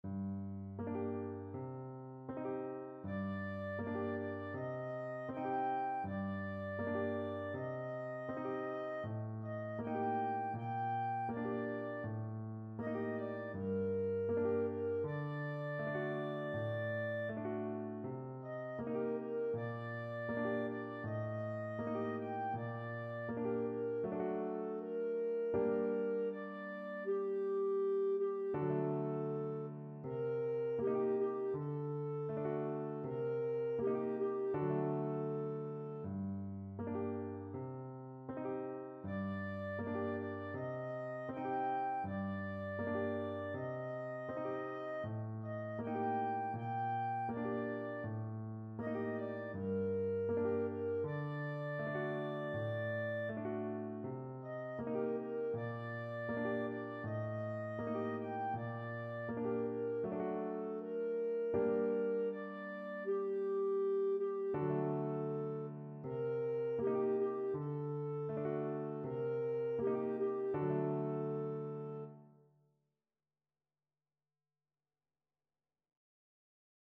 Clarinet
G minor (Sounding Pitch) A minor (Clarinet in Bb) (View more G minor Music for Clarinet )
4/4 (View more 4/4 Music)
Andante
Traditional (View more Traditional Clarinet Music)